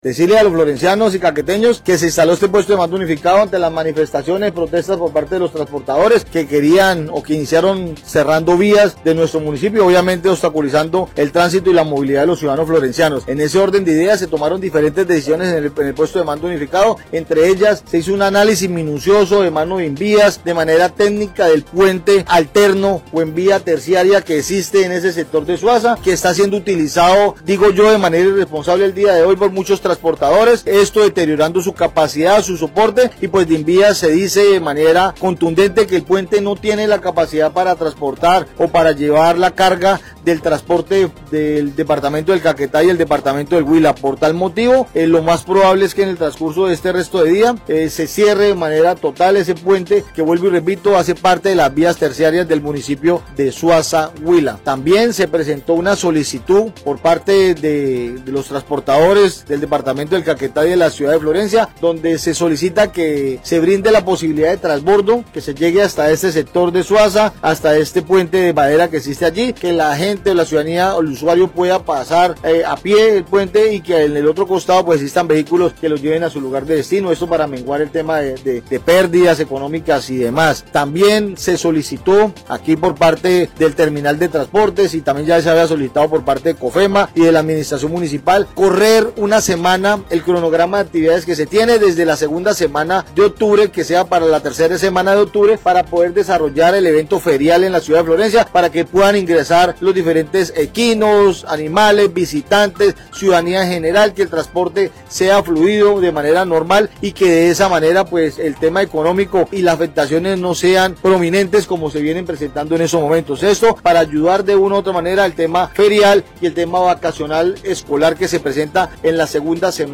Así lo dio a conocer el secretario de gobierno municipal Carlos Mora Trujillo, quien dijo que, el INVIAS ha informado sobre las pocas condiciones técnicas y de seguridad existentes en dicha estructura lo cual pone en riesgo la integridad de quienes transite por el lugar.